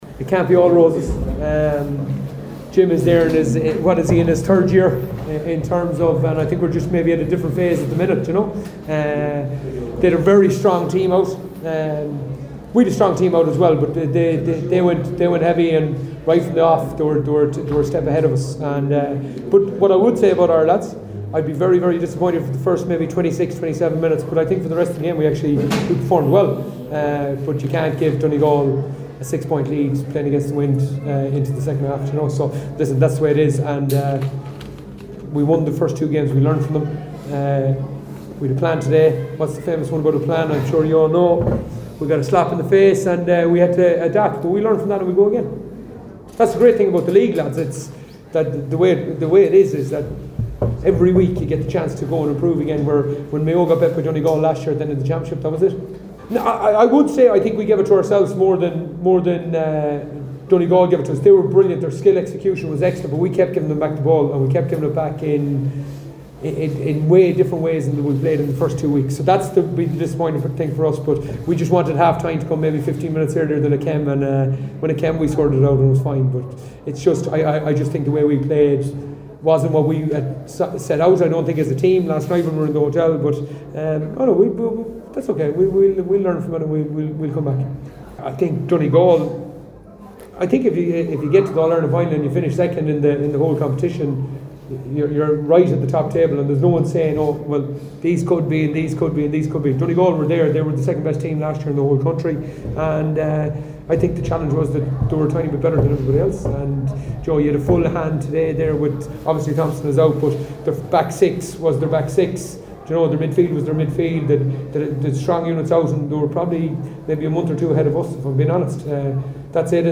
Moran told the assembled media afterwards that Donegal were further along the line in terms of development and are one of the best teams in the country…